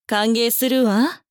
大人女性│女魔導師│リアクションボイス│商用利用可 フリーボイス素材 - freevoice4creators
喜ぶ